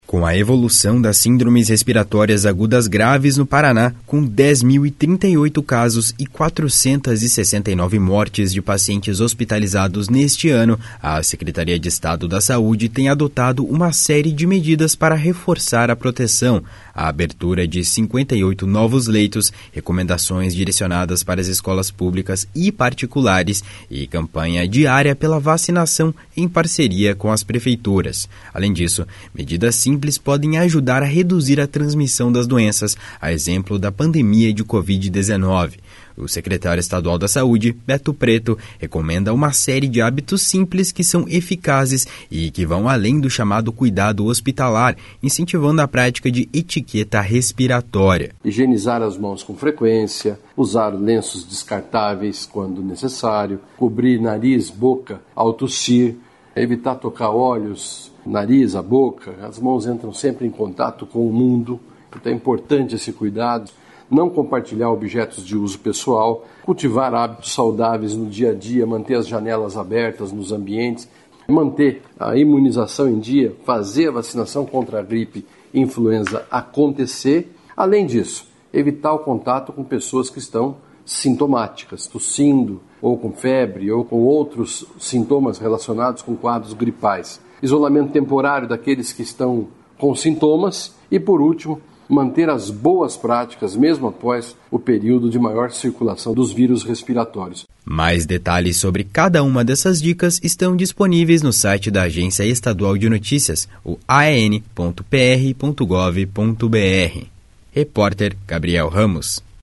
O secretário estadual da Saúde, Beto Preto, recomenda uma série de hábitos simples que são eficazes e que vão além do chamado cuidado hospitalar, incentivando a prática de “etiqueta respiratória”. // SONORA BETO PRETO //